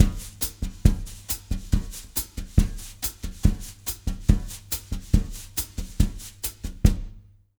140BOSSA04-L.wav